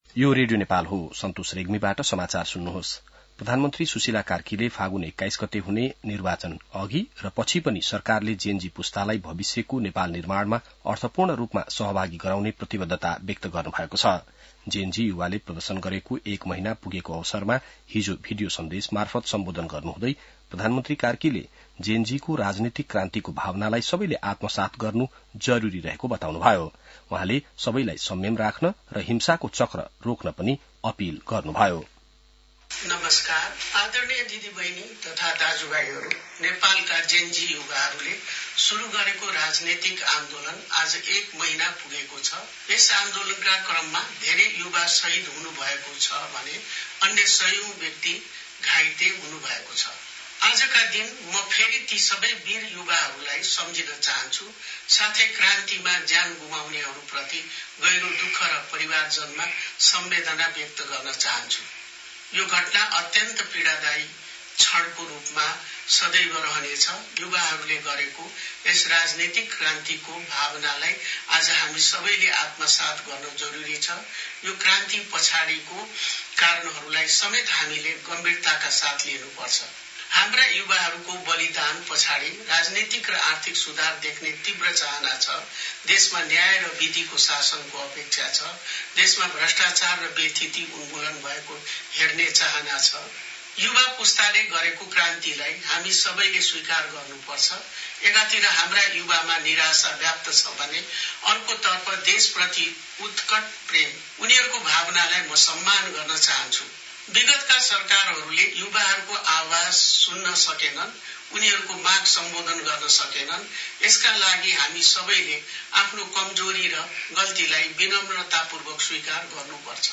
बिहान ६ बजेको नेपाली समाचार : २४ असोज , २०८२